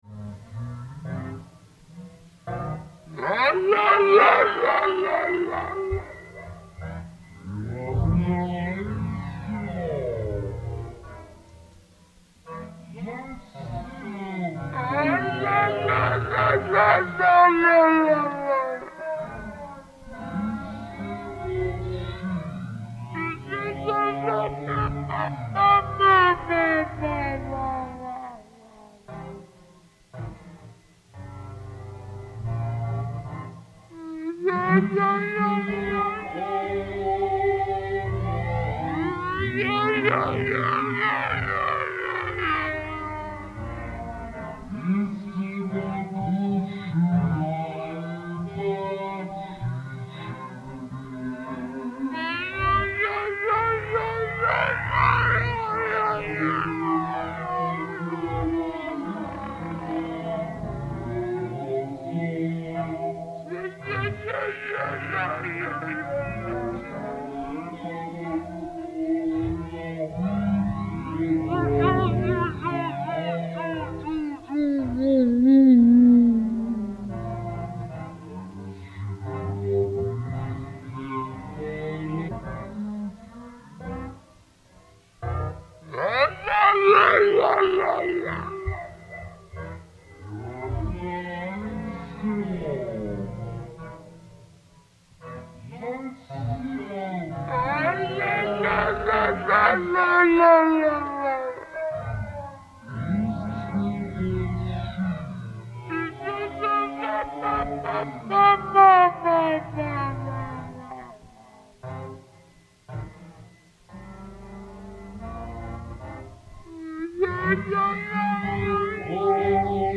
Recorded live at home in Manhattan.
alto clarinet, alto clarinet mouthpiece, vocals, fx
vocals, percussion, peck horn, fx
Stereo (722 / Pro Tools)